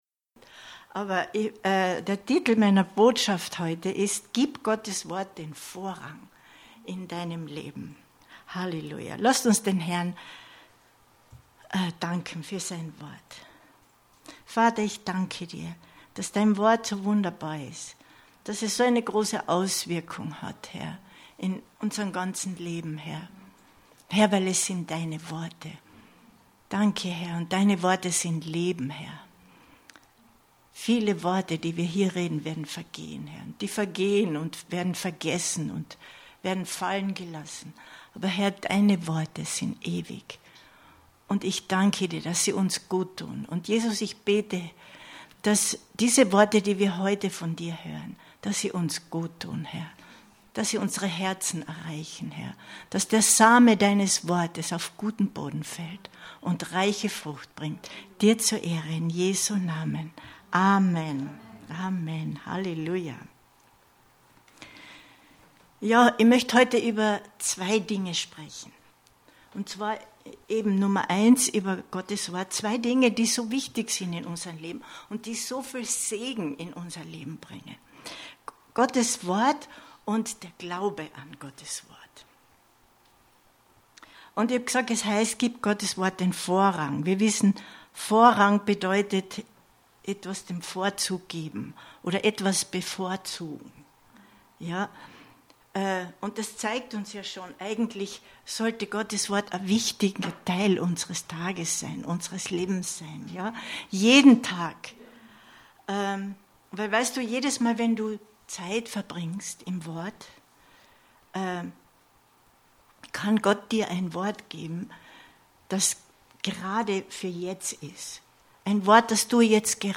Info Info Gib Gottes Wort den Vorrang 08.08.2021 Predigt herunterladen